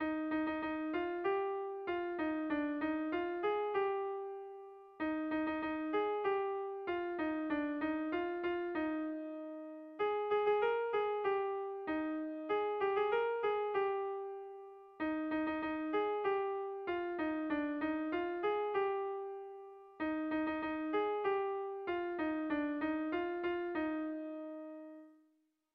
Zortziko txikia (hg) / Lau puntuko txikia (ip)
A1A2BA1A2